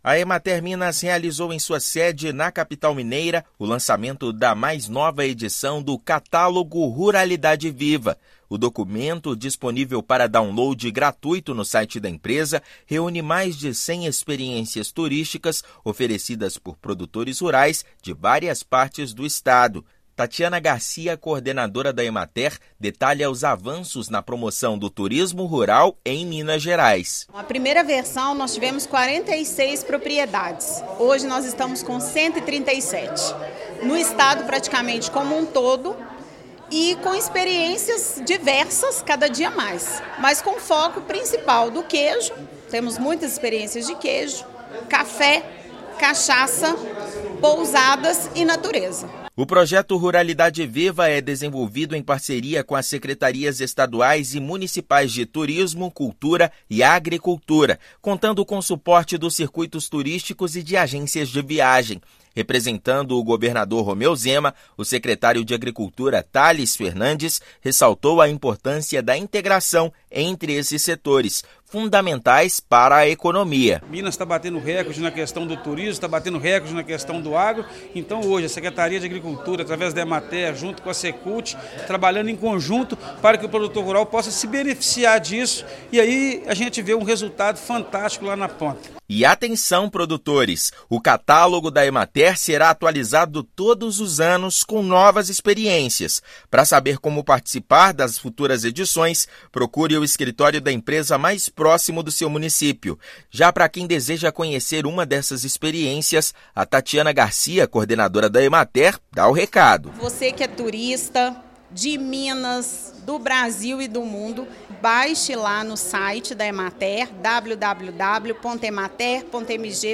Publicação está disponível no site da empresa e traz informações de 137 propriedades que recebem visitantes. Ouça matéria de rádio.